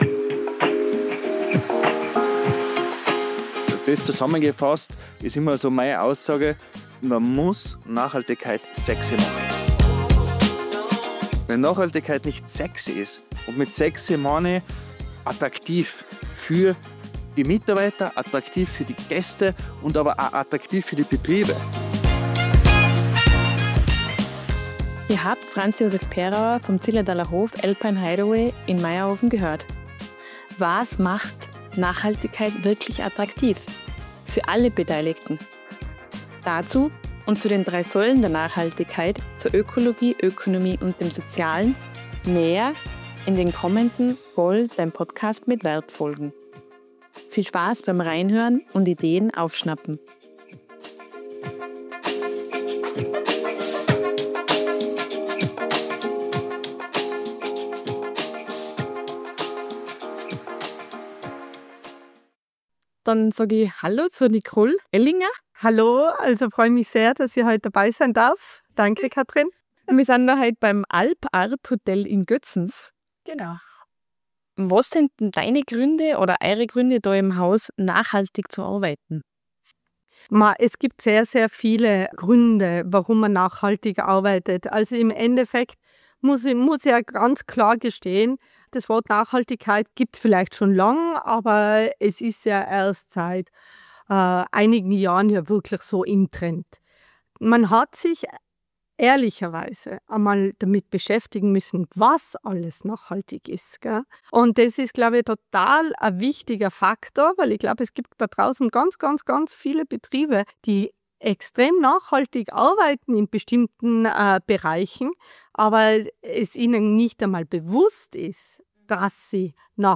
TouristikerInnen sprechen über innovative Ansätze, geben Praxisinputs und Hilfestellungen für nachhaltiges Arbeiten.